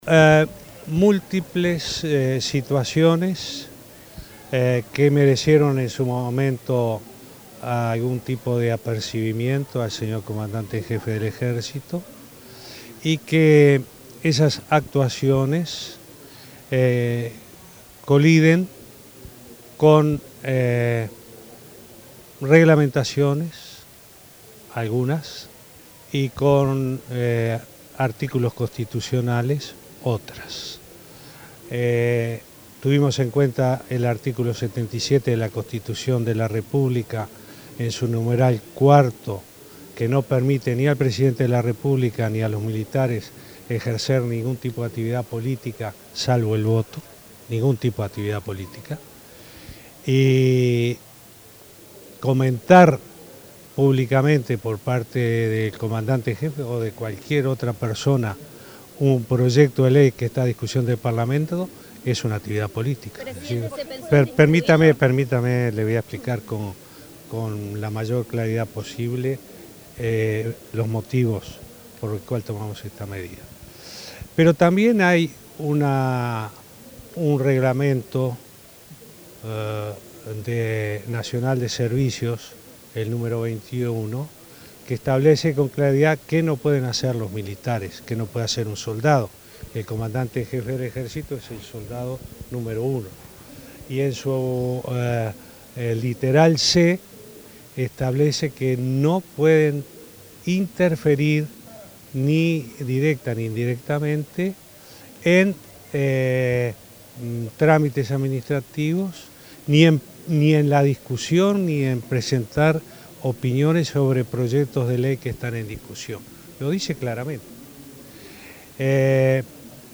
“El comandante en jefe del Ejército actúa de buena fe y con la lealtad institucional que deben tener las Fuerzas Armadas, pero se equivocó y, en función de eso, es sancionado”, dijo el presidente Tabaré Vázquez. Explicó que la sanción se basó en el artículo 77 de la Constitución de la República y el Reglamento Nacional de Servicios 21, que establece que los militares no pueden interferir con opiniones sobre proyectos de ley.